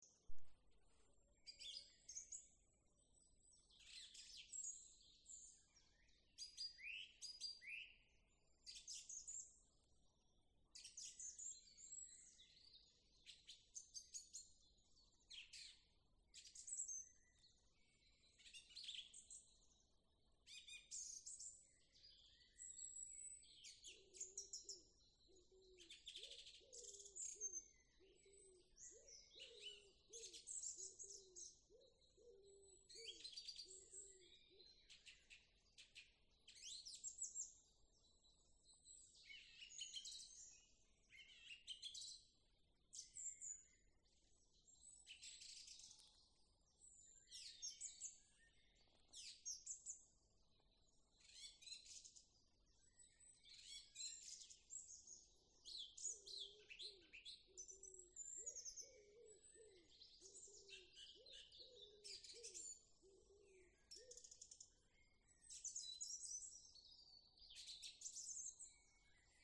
Song Trush, Turdus philomelos
Administratīvā teritorijaViļakas novads
StatusSinging male in breeding season